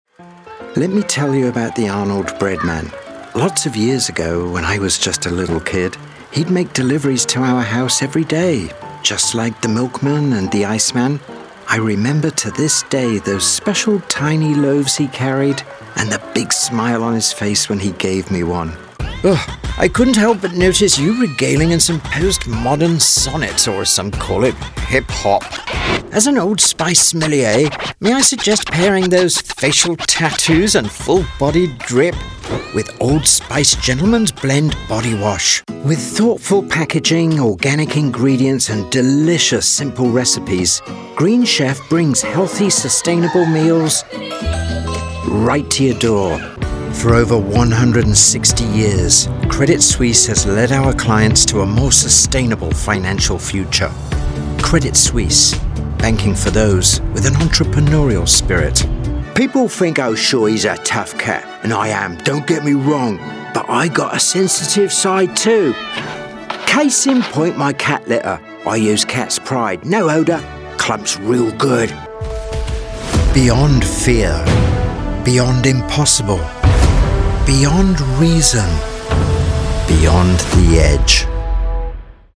I can be authoritative, friendly & conversational, nurturing and a bit cheeky - occasionally all at the same time!
English - British RP
RP but also a variety of regional British dialects
Middle Aged